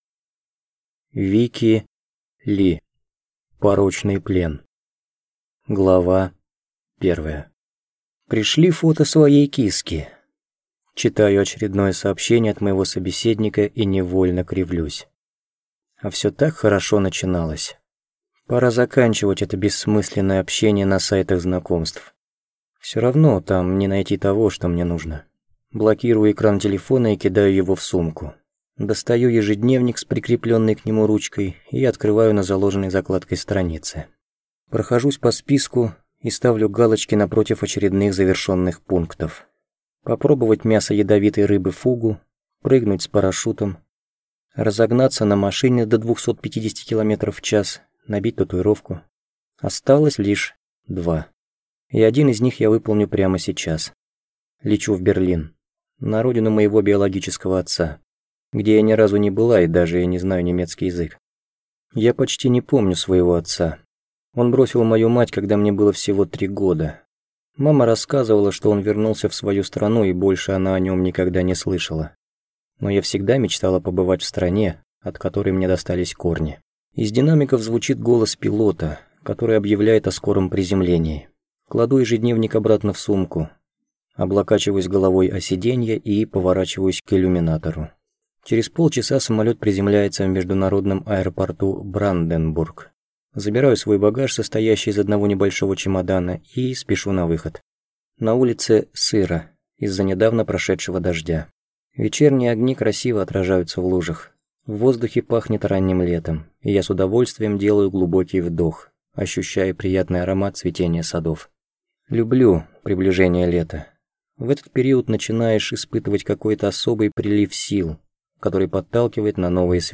Аудиокнига Порочный плен | Библиотека аудиокниг